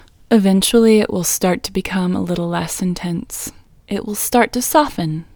IN Technique First Way – Female English 12